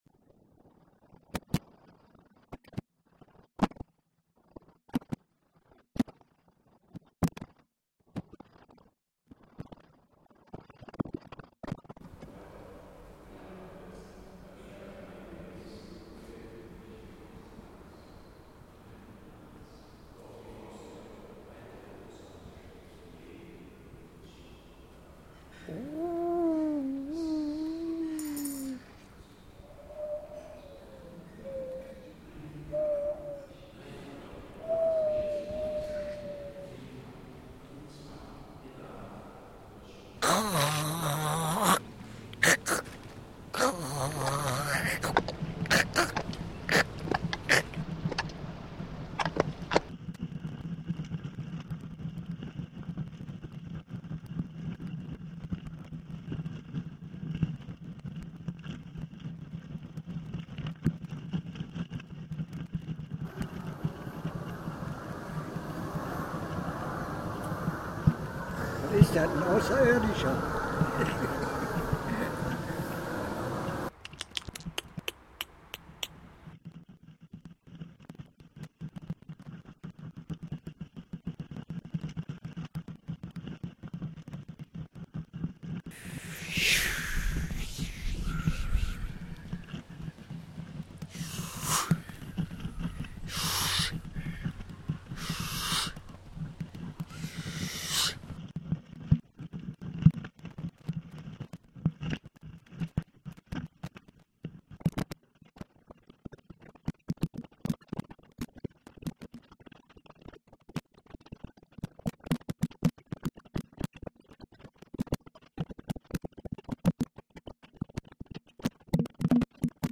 Das Projekt „LANDSCAPE ABTEIBERG“ im Arbeitslosenzentrum Mönchengladbach e.V. macht den Stadtteil rund um den Abteiberg hörbar und sichtbar und regt damit die individuelle Wahrnehmung an.